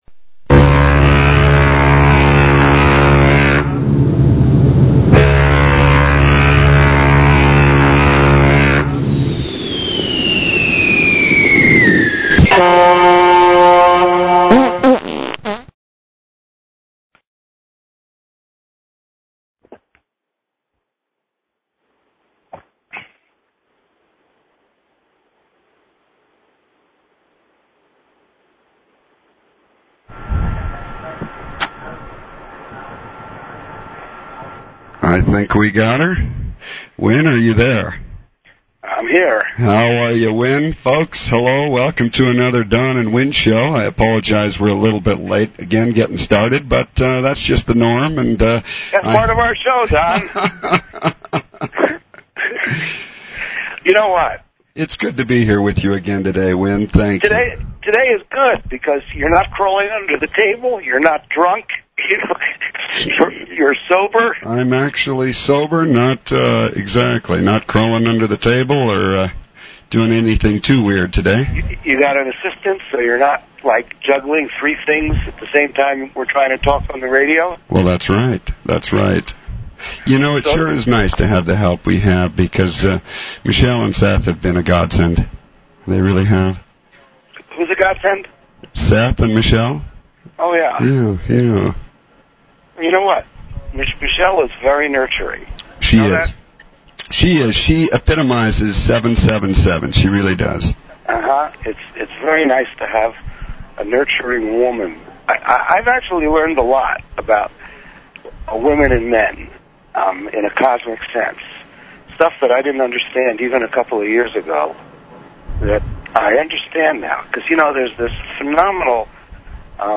Talk Show Episode
This is not your typical show!